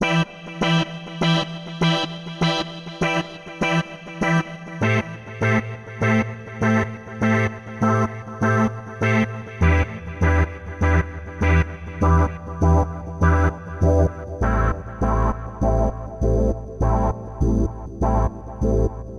Tag: 100 bpm Chill Out Loops Organ Loops 3.23 MB wav Key : Unknown